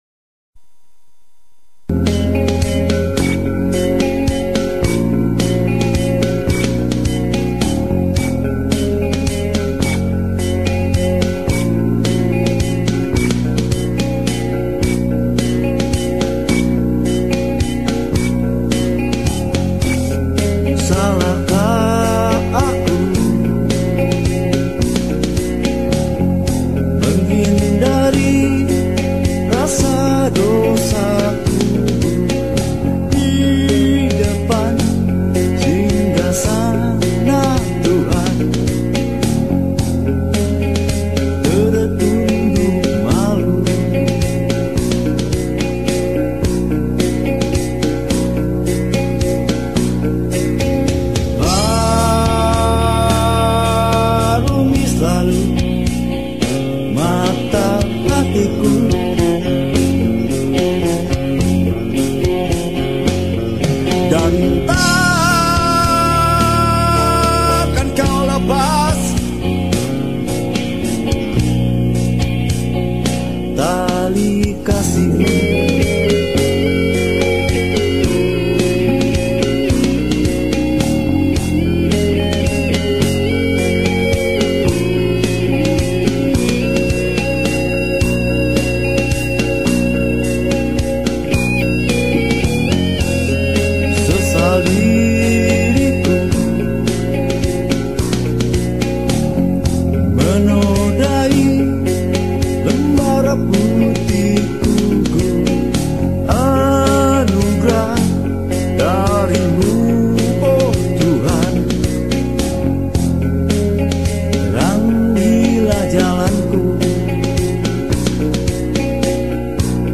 dan ini salah satu favorit karena lagunya tenang